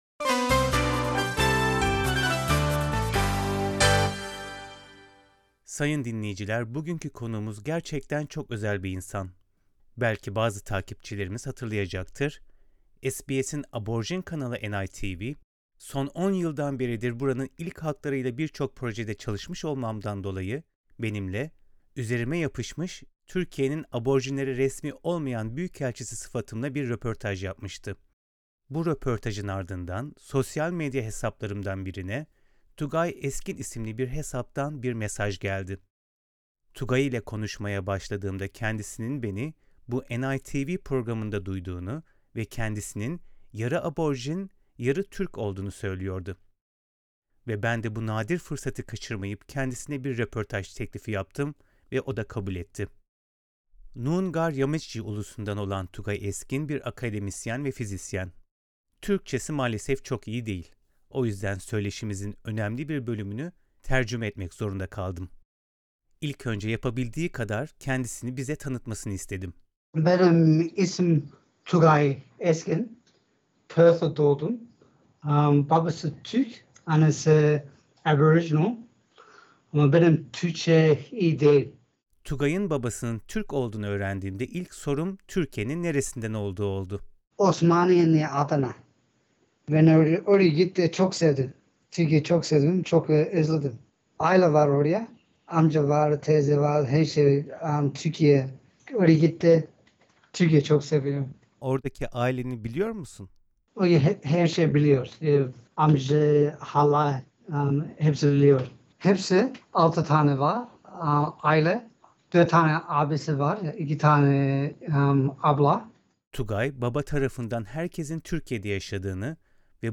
Türkçesi maalesef çok iyi değil, o yüzden söyleşimizin önemli bir bölümünü tercüme etmek zorunda kaldık.